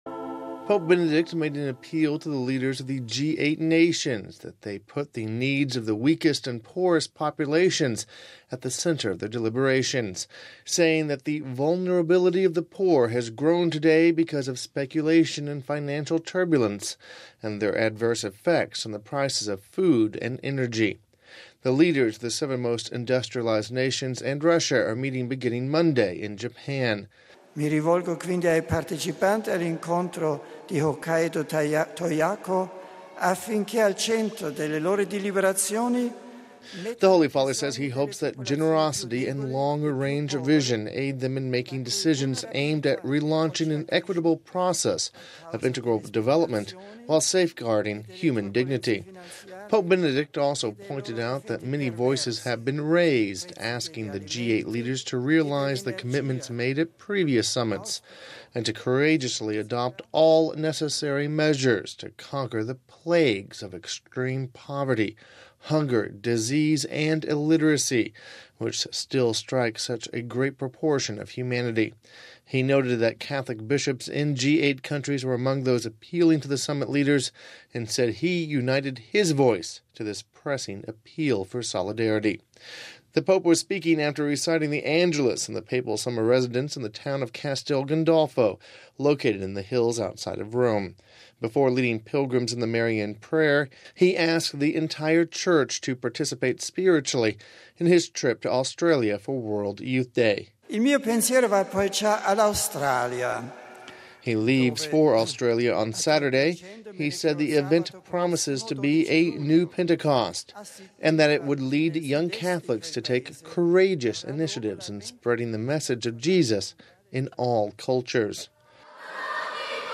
Home Archivio 2008-07-06 18:39:22 Pope Benedict Makes Appeal Before G8 Summit (07 July 08 - RV) Pope Benedict appealed for G8 Leaders to not forget the poor during the Japan meetings. We have this report...